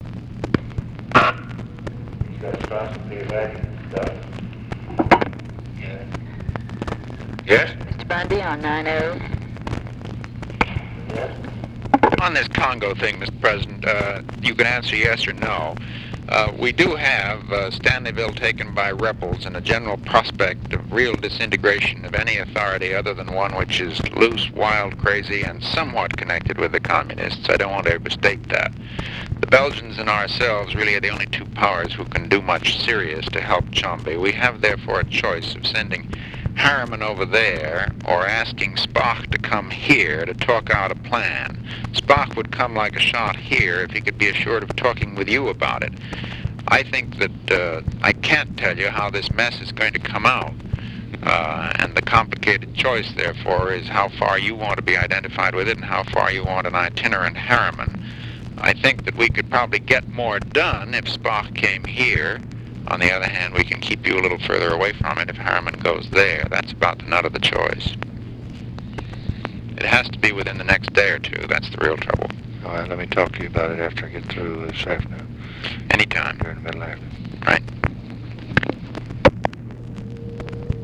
Conversation with MCGEORGE BUNDY, August 6, 1964
Secret White House Tapes